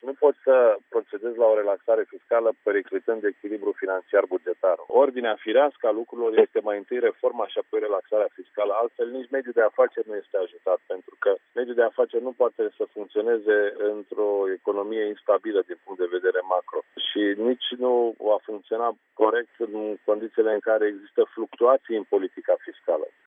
Prim vicepreşedintele PNL, Cătălin Predoiu, a precizat că Guvernul trebuie să vină cu propuneri de compensare a măsurilor de relaxare fiscală prevăzute în noul Cod :